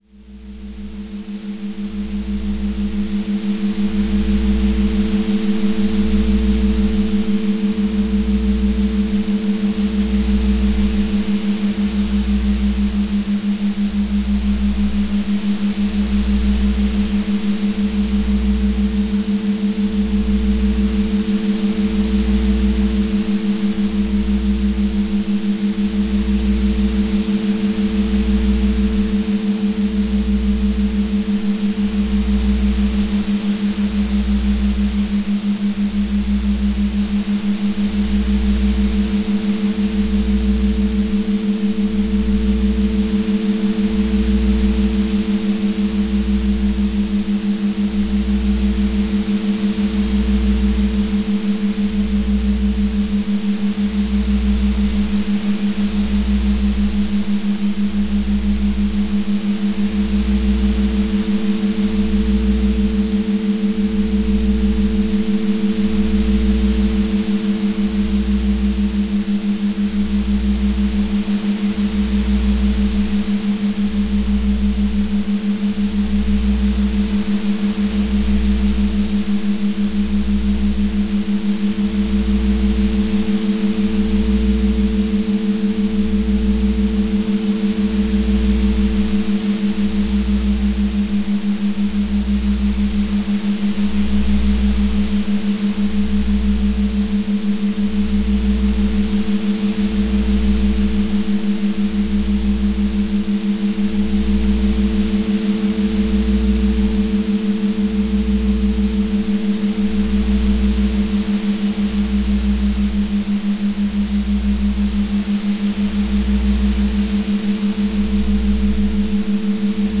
Theta 6hz + lo-fi texture.